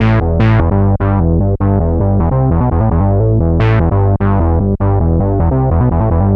Techno bass loops soundbank 5
Free MP3 techno music bass loops 5